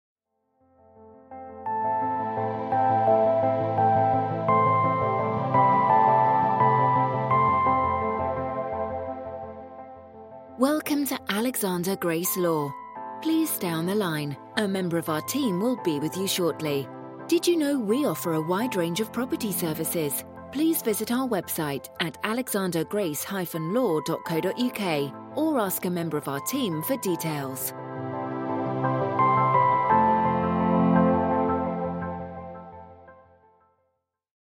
I can arrange voice over recordings for music on hold and IVR projects for your business.
I only use industry standard voice artists for my voice overs to create a professional finished product.
Messages On Hold Demo 2